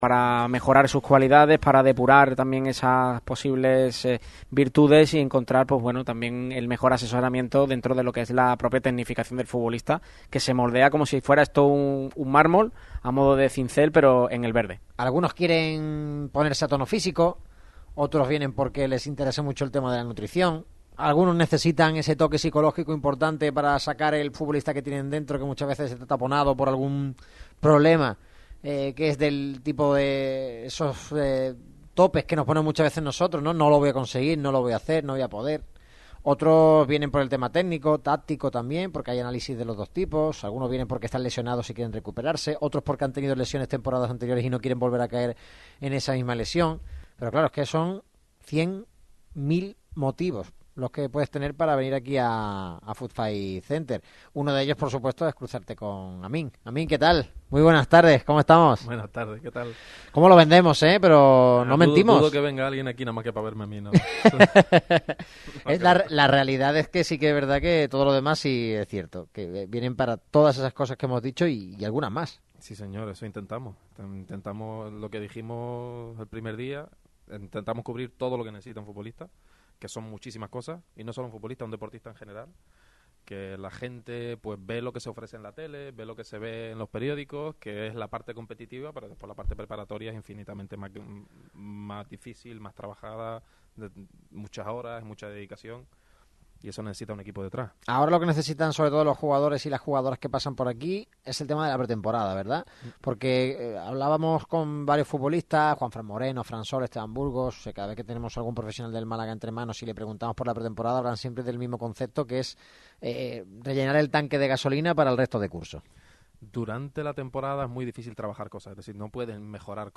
La radio que sintoniza tu pasión acude a la llamada de los amigos de Footfay Center.